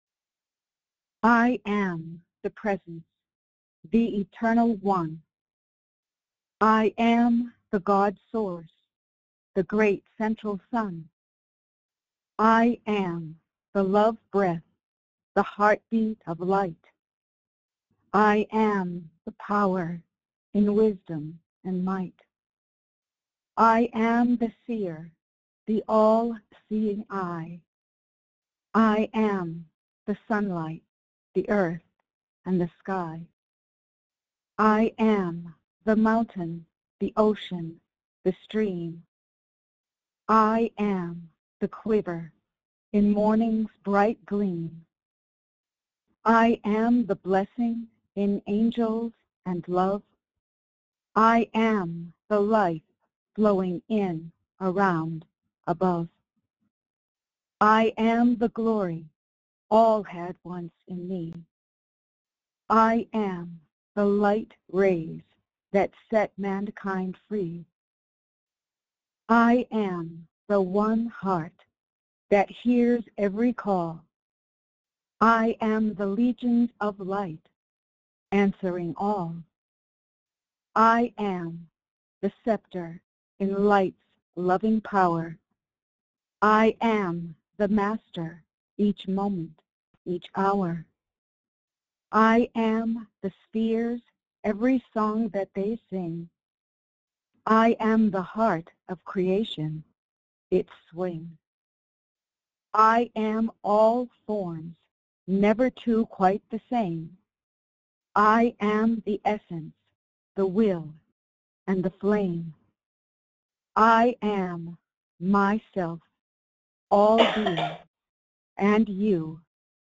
Follow along in group meditation with Lord Sananda (Yeshua)